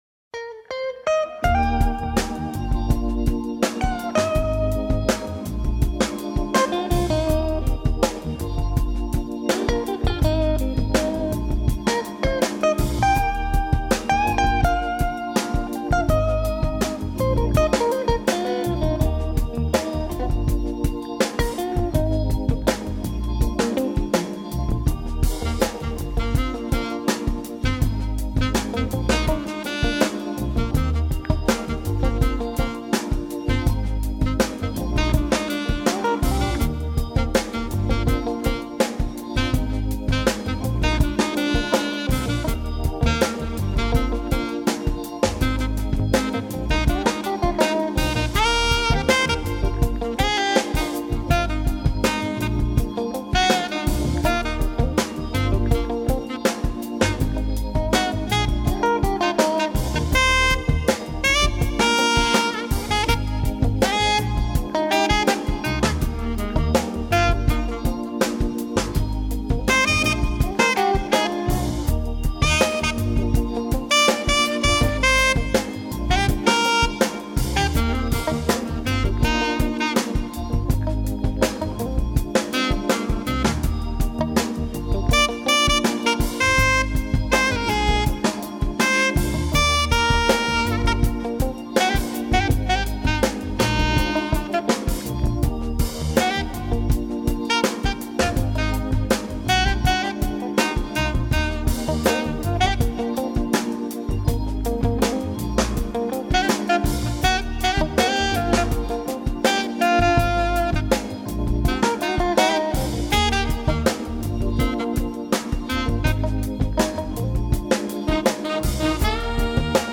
Groovy jazz